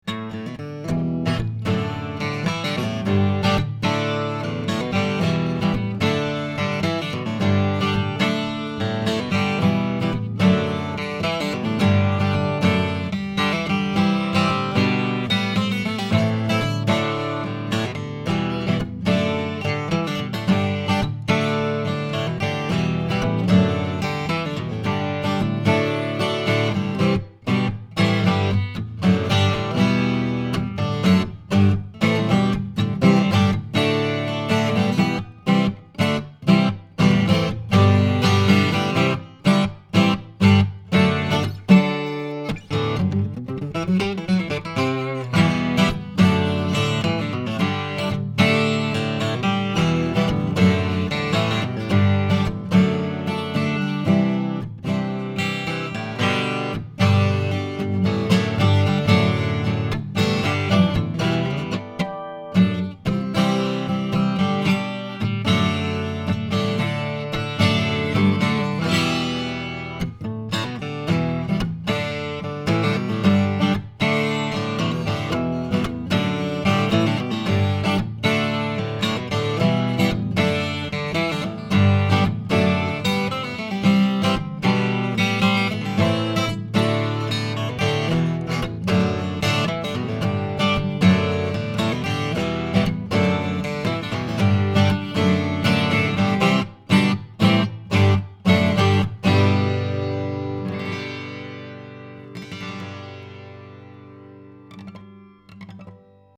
1993 GUILD JF55-12 NTE 12-String Guitar
Here are 19 quick, 1-take MP3 sound files of myself playing this guitar, to give you an idea of what to expect. The guitar is a powerful Jumbo 12-string, and delivers the highly-coveted Guild 12-string acoustic sound with a nice balance between the upper and lower registers, and of course, the amazing 12-string "ring" effect for which Guilds are legendary. These files are using a vintage Neumann U87 mic into a Sony PCM D1, flash recorder, with MP3s made in Logic, with no EQ, compression, or any other special effects.
It has a powerful voice, but when played softly it also has a very fine shimmering quality.